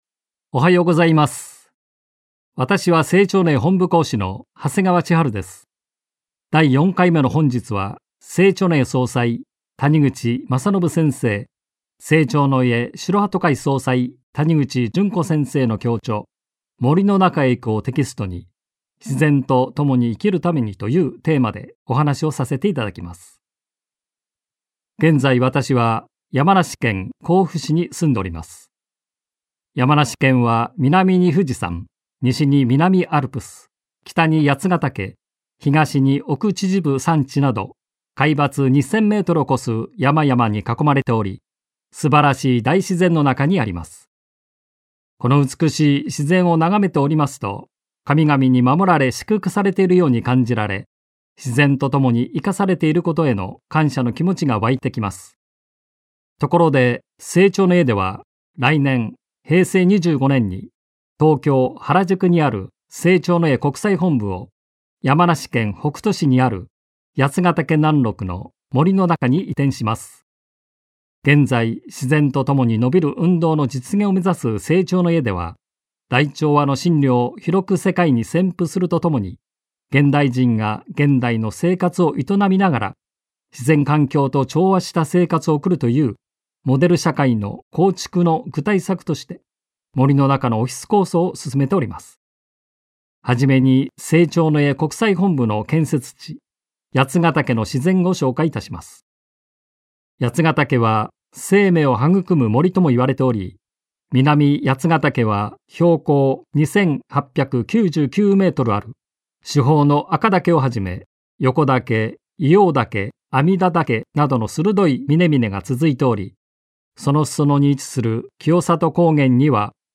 生長の家がお届けするラジオ番組。